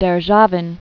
(dĕr-zhävĭn, dyĭr-), Gavriil Romanovish 1743-1816.